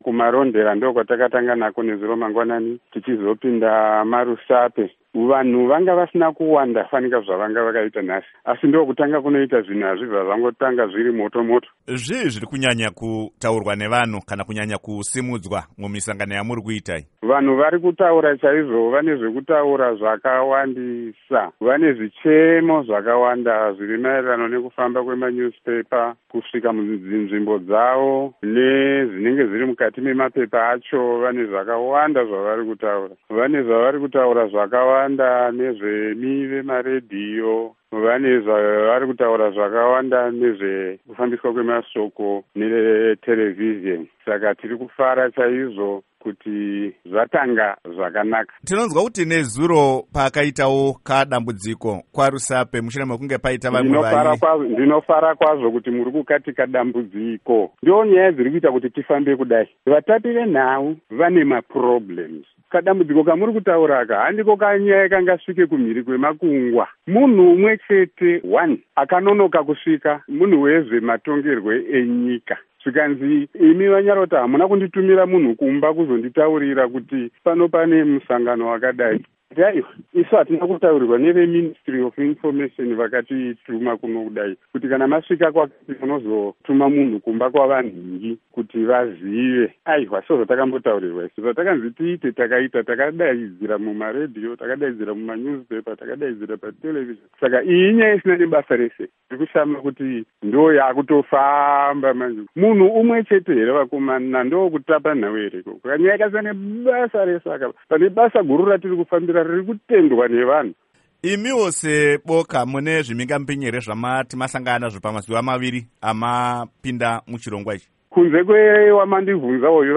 Hurukuro naVaGeoff Nyarota